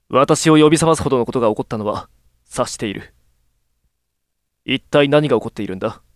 性別：男